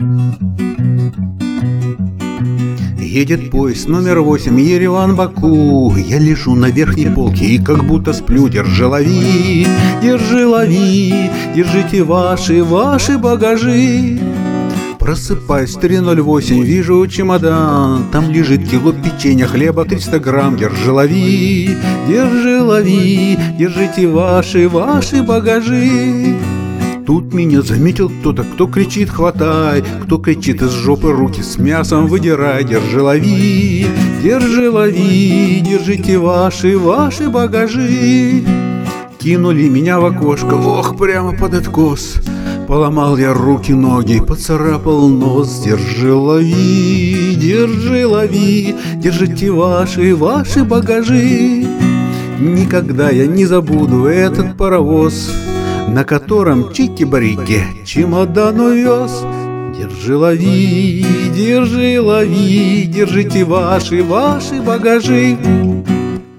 старая дворовая песня
• Жанр: Шансон